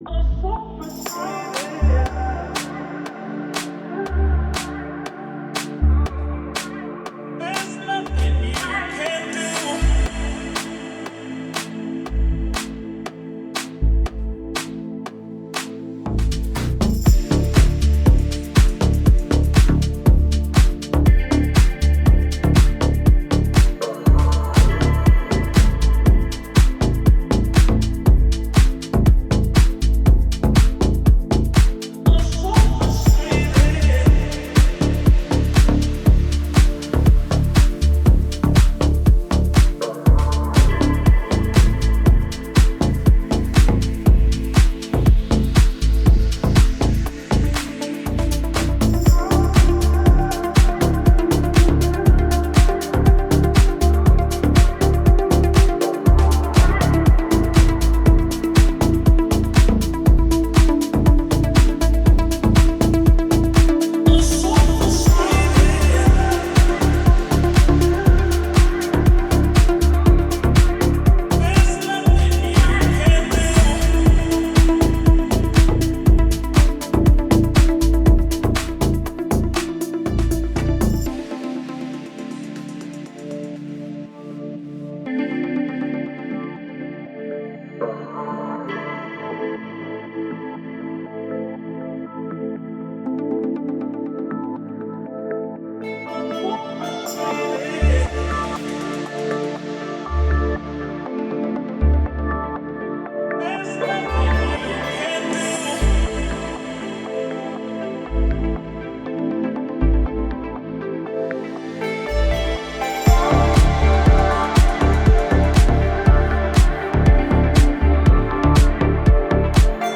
это глубокая и атмосферная трек в жанре deep house